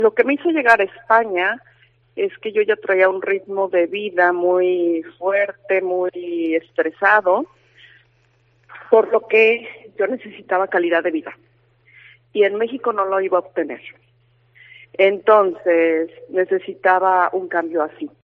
En una entrevista en Cope Ourense, señaló que le gustaría quedarse a vivir en Ourense.